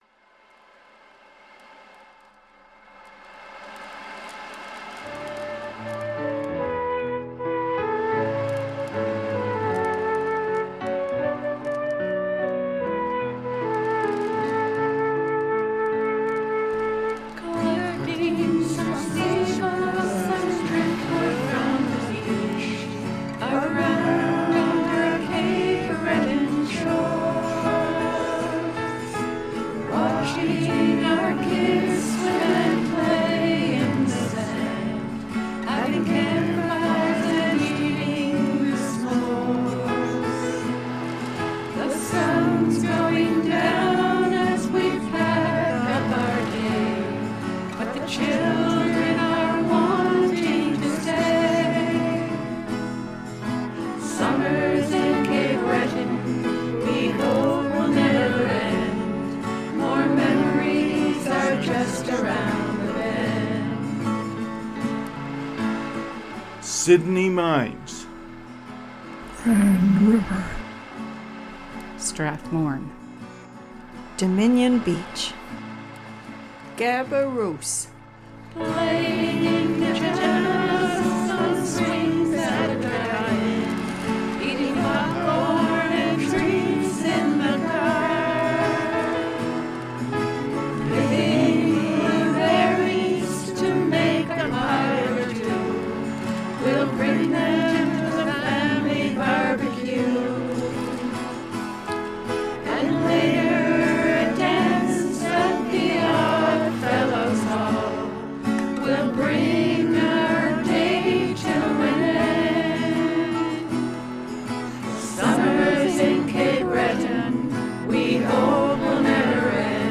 Hospice Harmonies is a group made up of palliative care staff, volunteers and patients at Hospice Cape Breton.
Some sing, some play instruments and some do both.